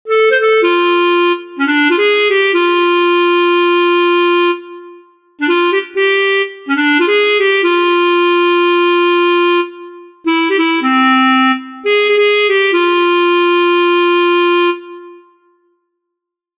blues.mp3